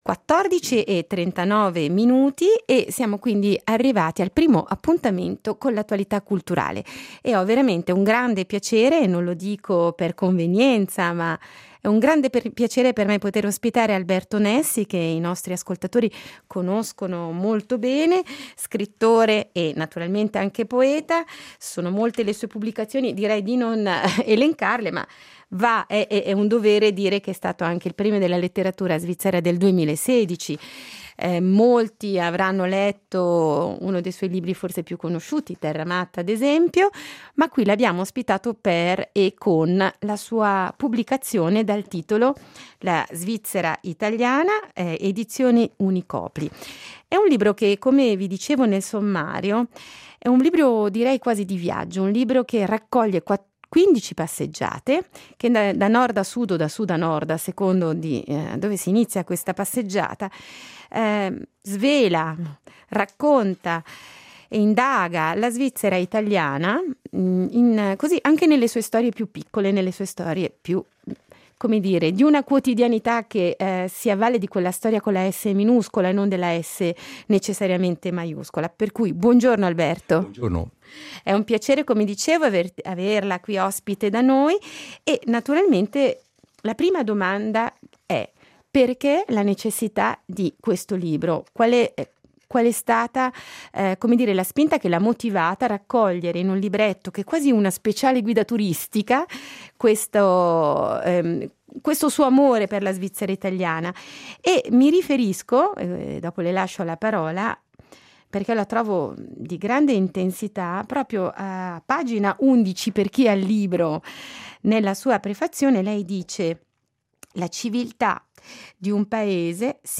Incontro con Alberto Nessi, scrittore e poeta, ospite di Passatempo per presentare il suo libro “Svizzera italiana”, ed. Unicopli, 2016.